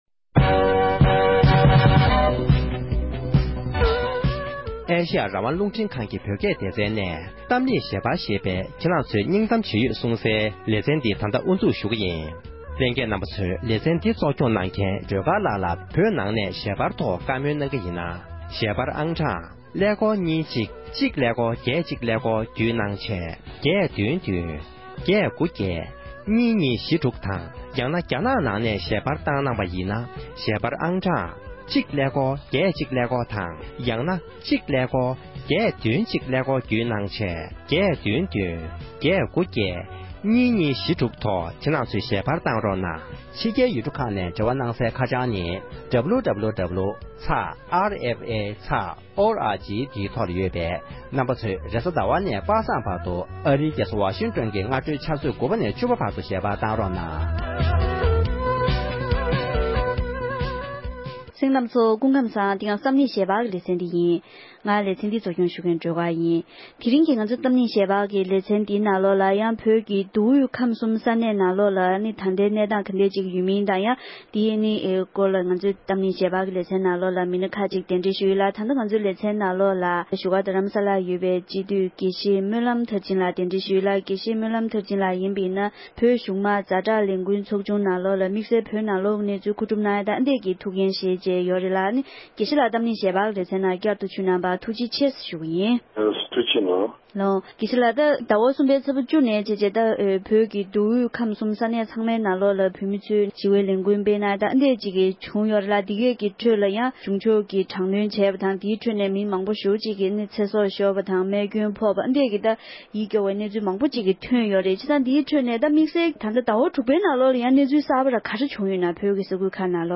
༄༅༎དེ་རིང་གི་གཏམ་གླེང་ཞལ་པར་ལེ་ཚན་ནང་བོད་ནང་རྒྱ་ནག་གཞུང་གིས་མུ་མཐུད་དྲག་གནོན་བྱེད་བཞིན་ཡོད་པ་དང་བོད་ཀྱི་ཁམས་ཁུལ་དུ་ཡང་བོད་མིའི་ངོ་རྒོལ་གྱི་ལས་འགུལ་ཐོན་བཞིན་ཡོད་པ་བཅས་ཀྱི་གནས་ཚུལ་ཁག་གི་ཐོག་ལ་བགྲོ་གྲེང་ཞུས་པ་ཞིག་གསན་རོགས་གནང༌༎
སྒྲ་ལྡན་གསར་འགྱུར།